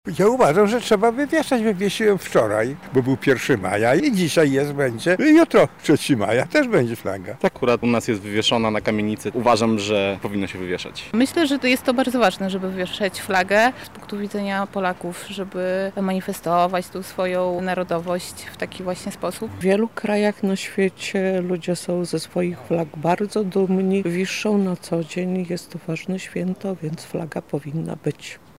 Jak mieszkańcy Lublina podchodzą do wywieszania przed domem flagi? [SONDA]
Spytaliśmy przechodniów, co sądzą o wywieszaniu flagi oraz czy sami to robią: